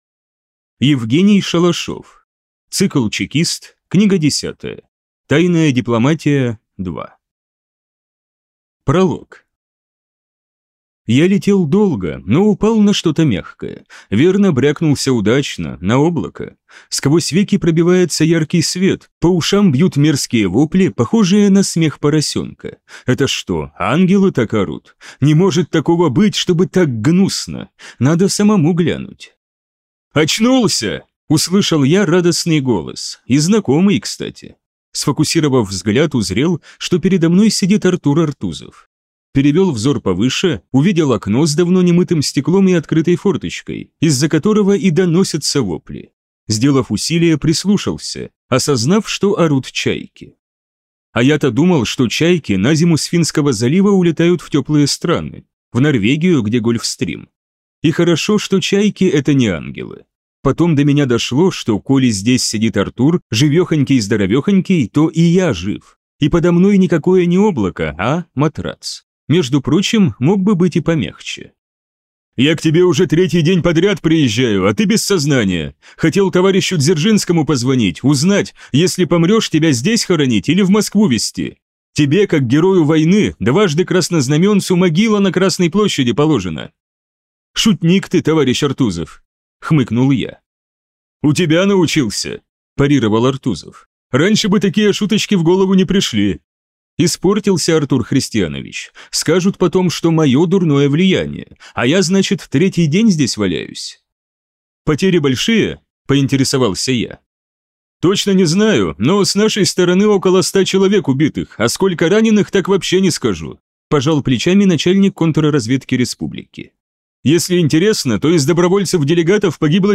Аудиокнига Чекист. Тайная дипломатия – II | Библиотека аудиокниг